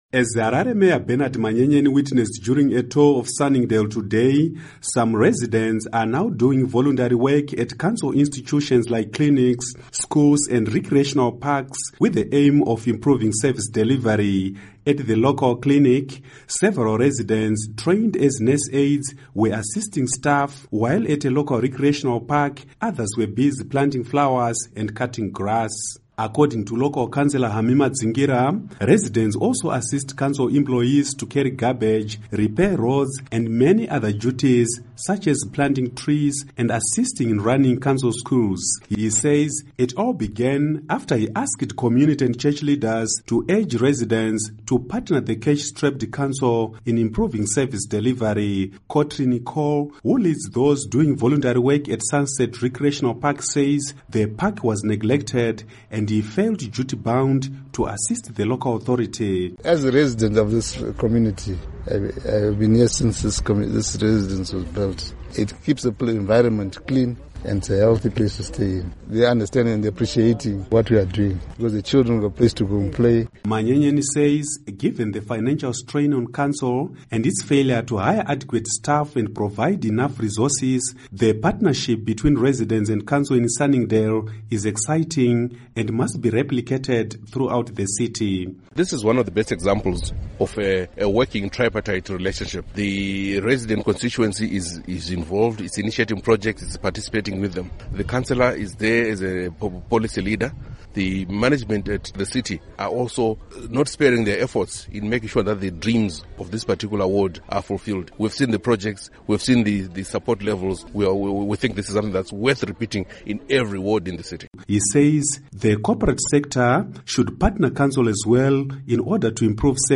Harare Residents' Report